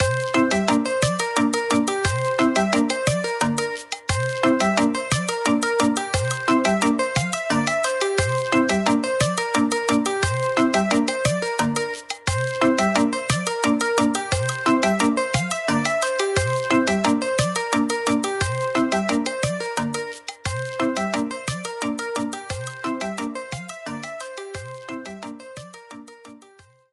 minigame theme rearranged